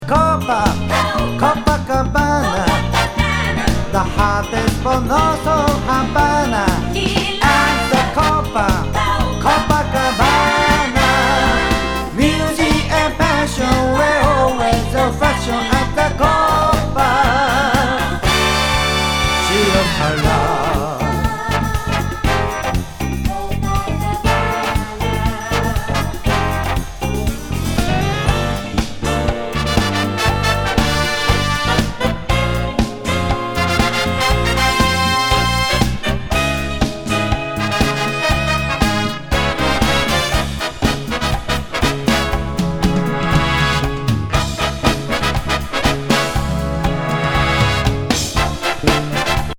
トロピカル